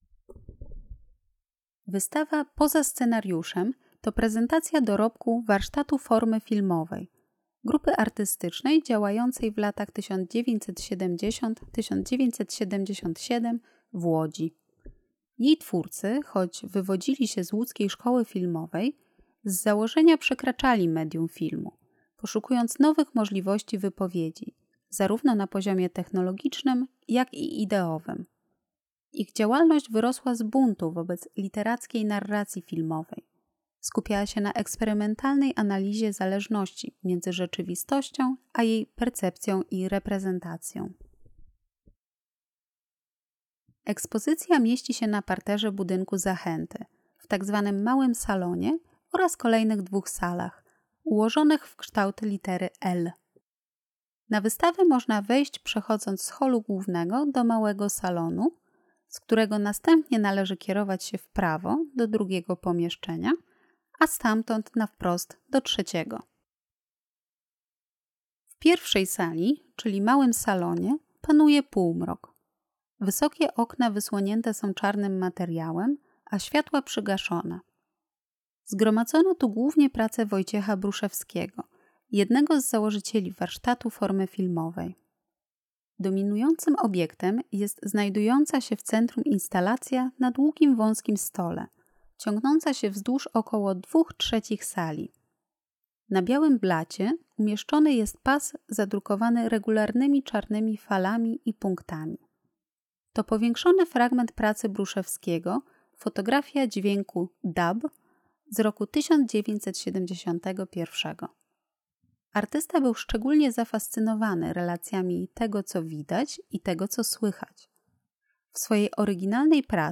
Audiodeskrypcja wystawy: Poza scenariuszem. Warsztat Formy Filmowej - Mediateka - Zachęta Narodowa Galeria Sztuki